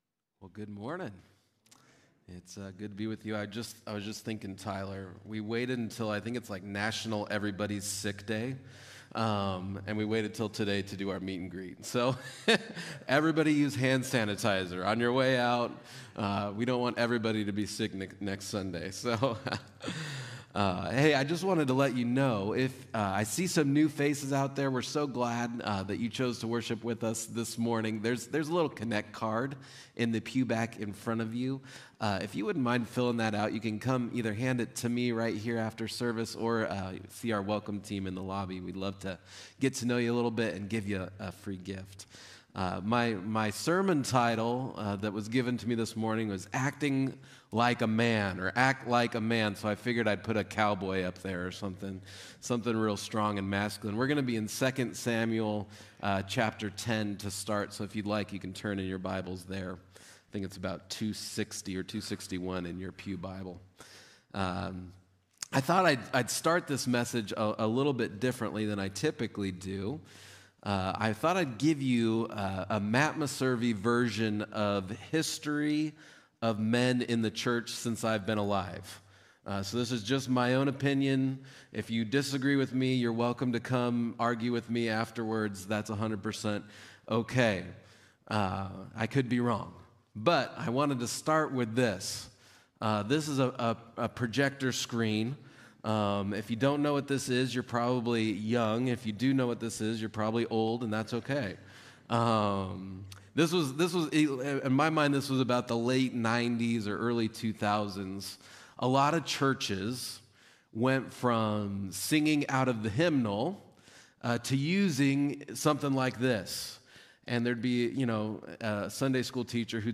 A message from the series "Wake Up!."